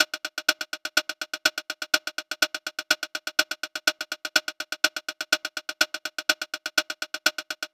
Bleep Hop Hat Loop.wav